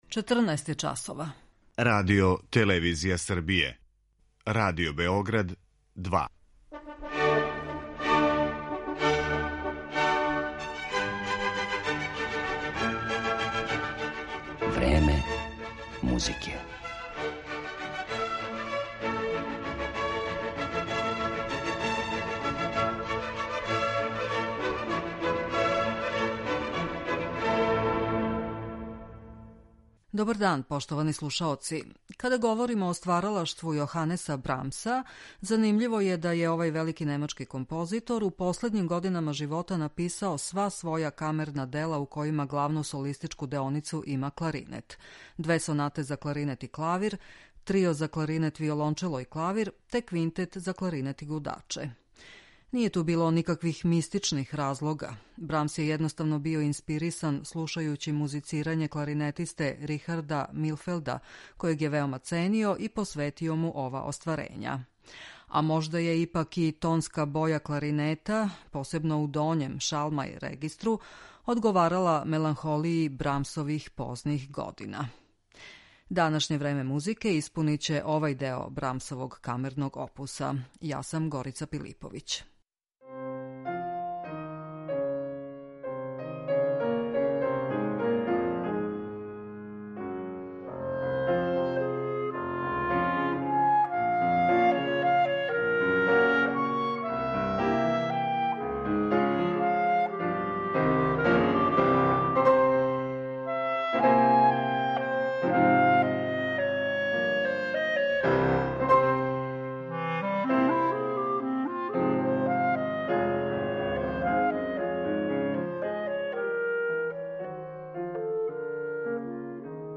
Ради се о две сонате за кларинет и клавир, Трију за кларинет, виолончело и клавир, те Квинтету за кларинет и гудаче.